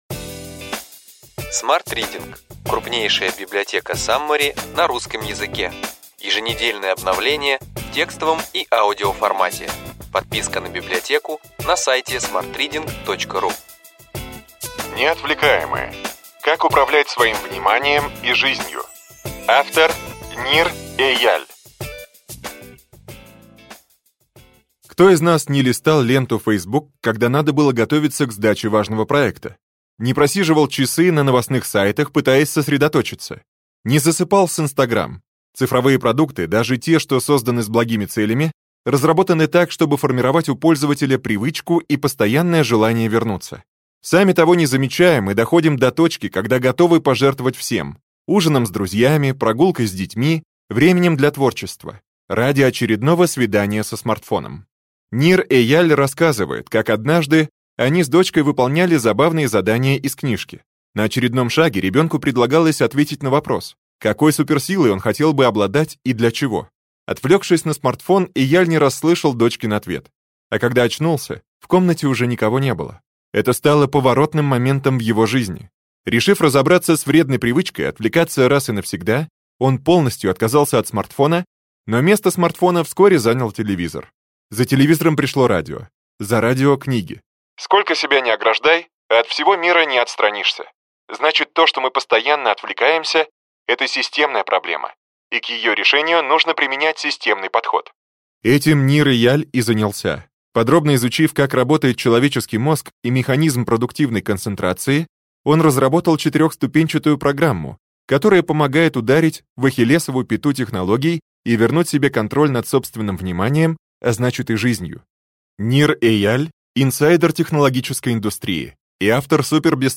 Аудиокнига Ключевые идеи книги: Неотвлекаемые. Как управлять своим вниманием и жизнью.